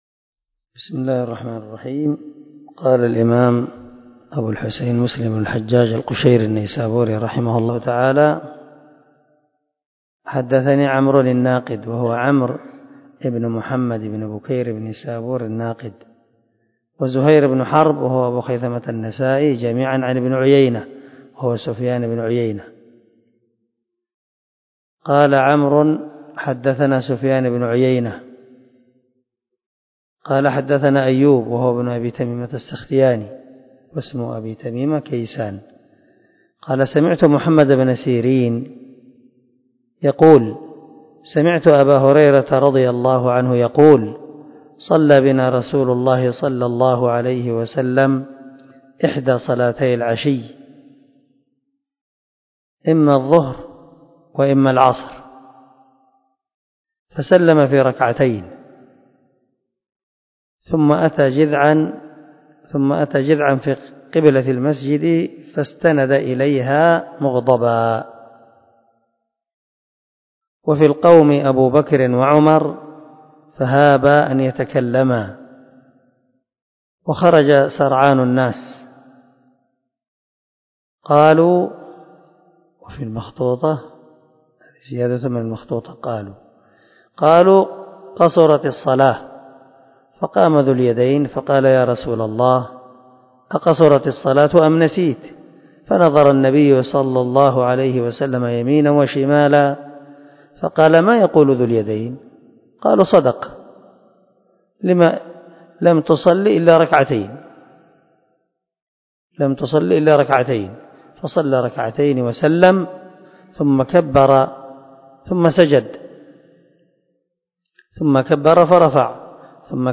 362الدرس 34 من شرح كتاب المساجد ومواضع الصلاة حديث رقم ( 573 ) من صحيح مسلم